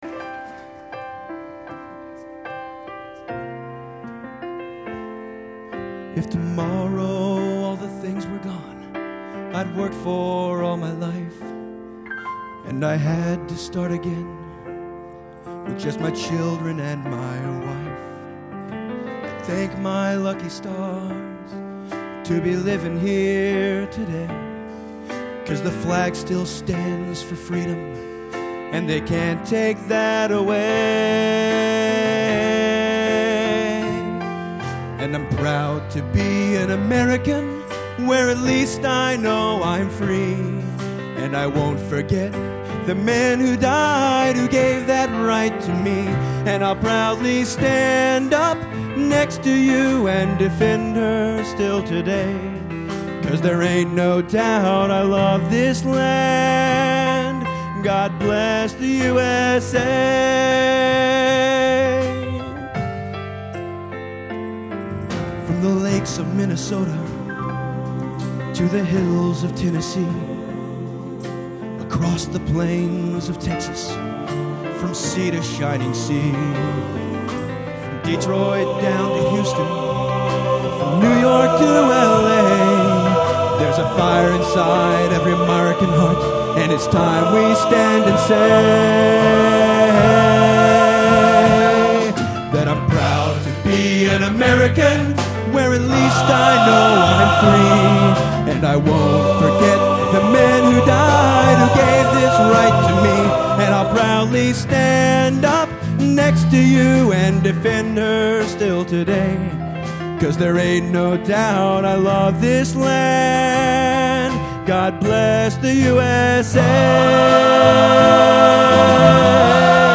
Location: Rossville High School, Rossville, Indiana
Genre: Patriotic | Type: